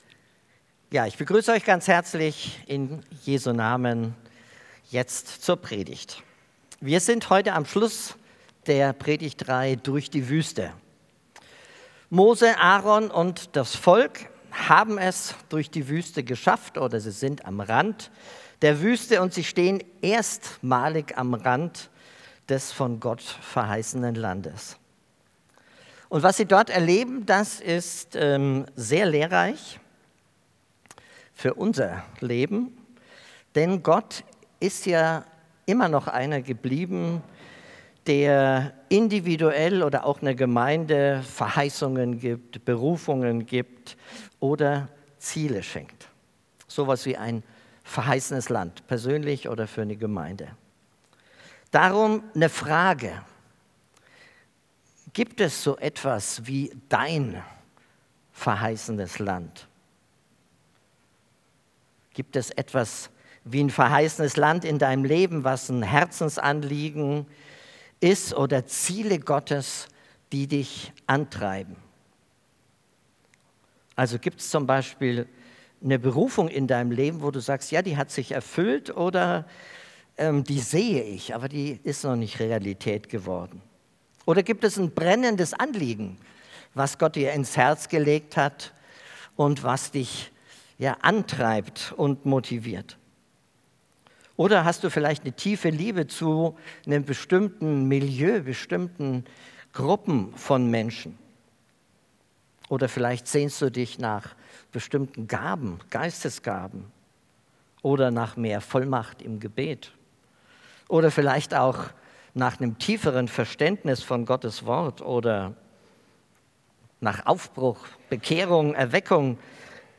Predigt Durch die Wüste